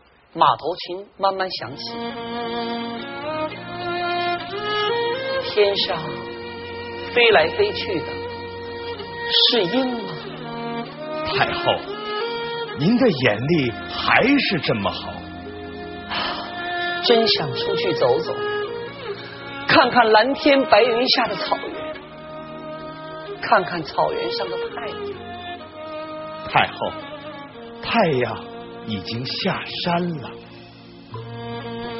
我把背景音乐截取出来，大神们帮我听听。